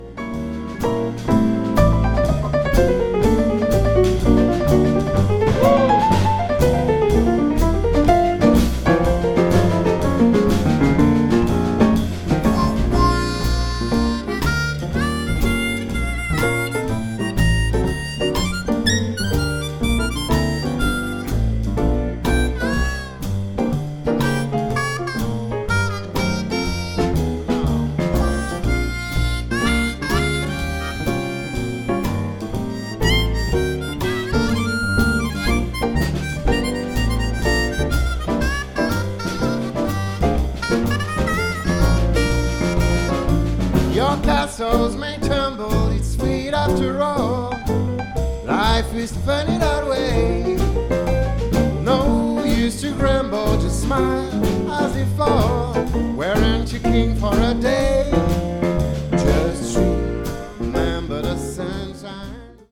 offre une rythmique idéale au piano virtuose
la voix suave et profonde
chant, harmonica, guitare
contrebasse
batterie